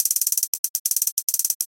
简单的帽子 2 140
Tag: 140 bpm Trap Loops Percussion Loops 295.49 KB wav Key : Unknown